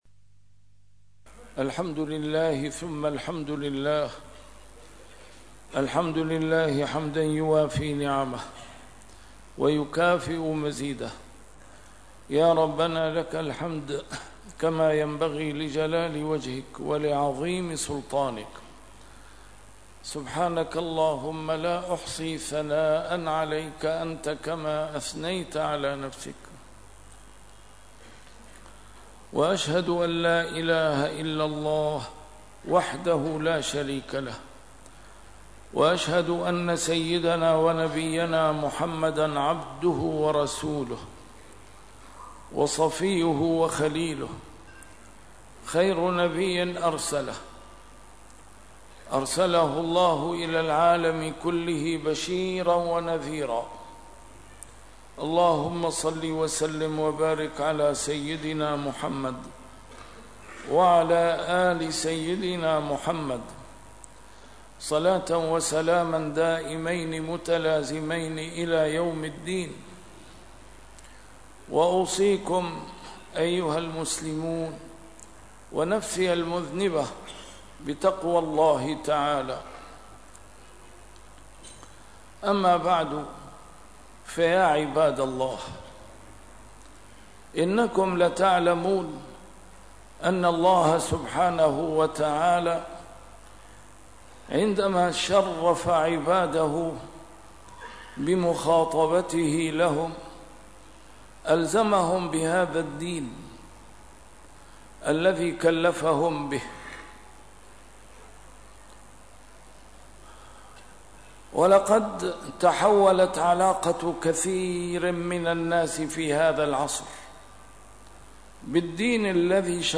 A MARTYR SCHOLAR: IMAM MUHAMMAD SAEED RAMADAN AL-BOUTI - الخطب - التمسك الصحيح بالدين يثمر الوقاية من الفساد ومن ثَمَّ الكوارث